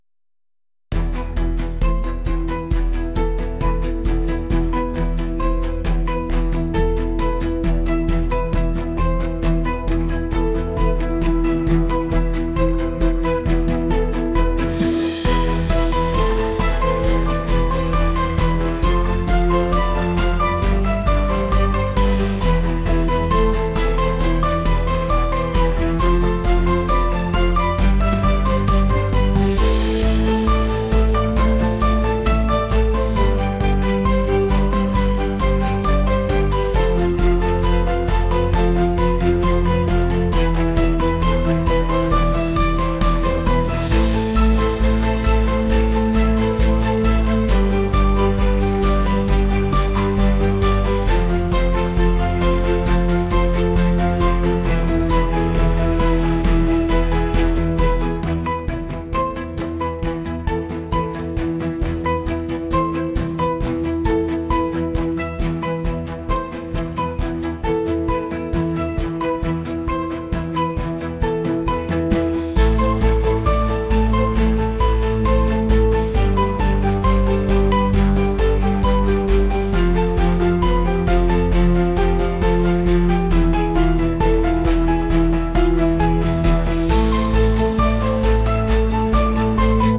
幻想的